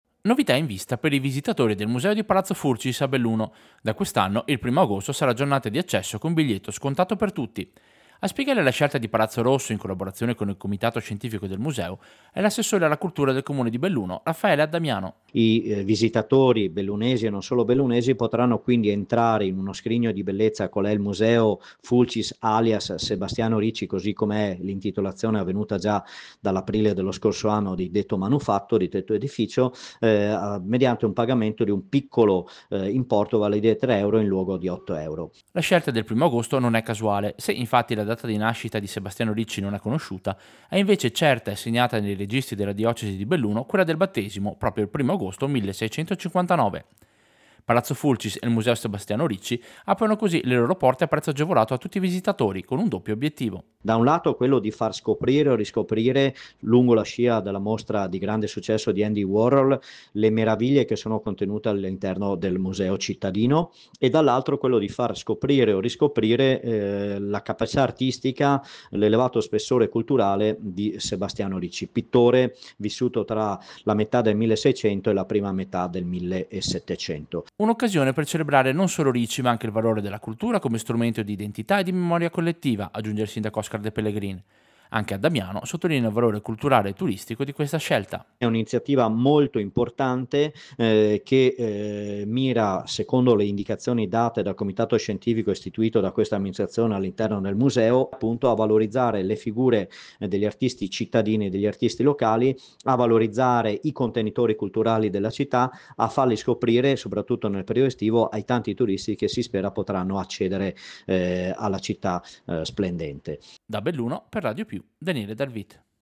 Servizio-Giornata-Sebastiano-Ricci-Museo.mp3